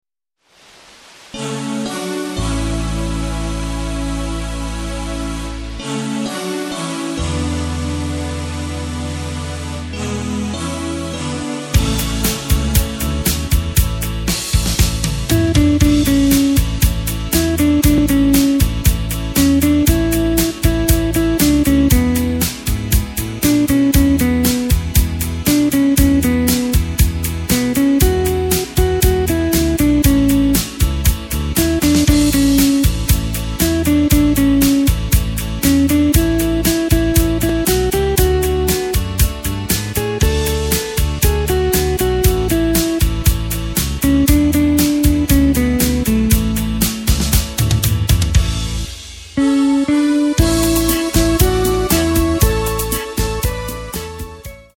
Takt:          4/4
Tempo:         117.00
Tonart:            A
Schlager aus dem Jahr 1983!